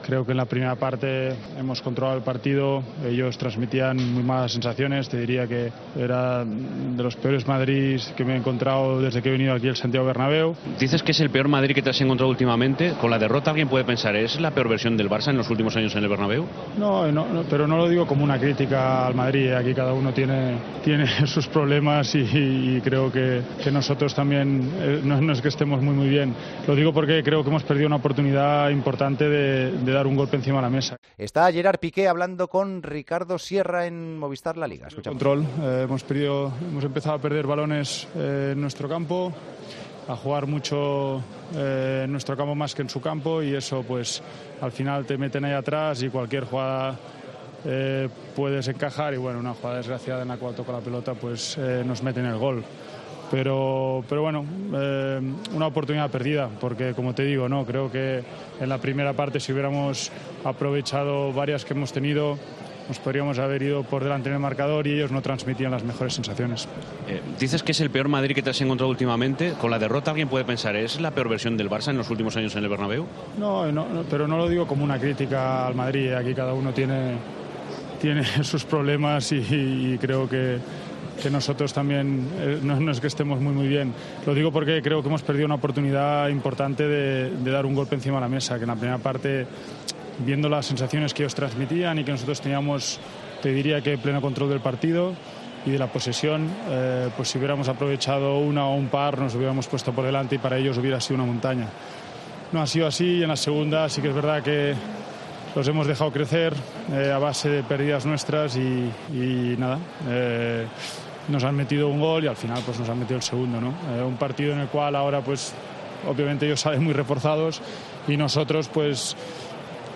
Gerard Piqué analizó el Clásico al término del partido, en el que el Barcelona salió derrotado por 2-0.